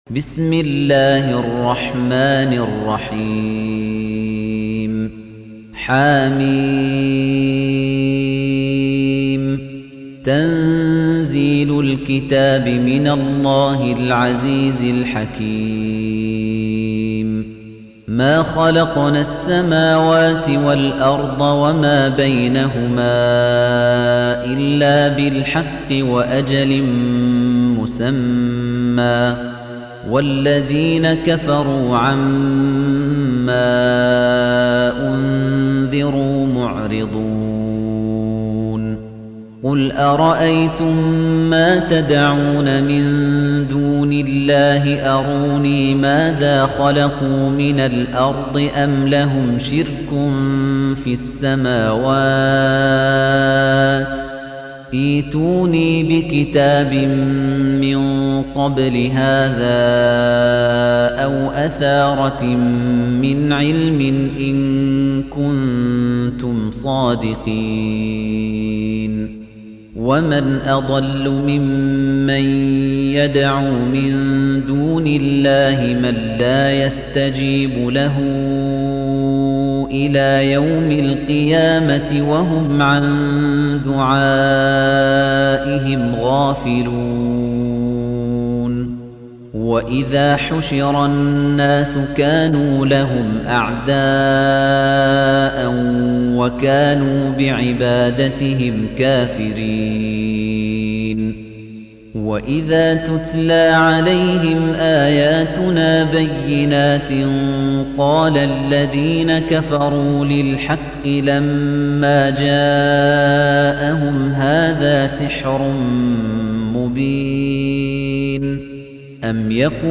Surah Repeating تكرار السورة Download Surah حمّل السورة Reciting Murattalah Audio for 46. Surah Al-Ahq�f سورة الأحقاف N.B *Surah Includes Al-Basmalah Reciters Sequents تتابع التلاوات Reciters Repeats تكرار التلاوات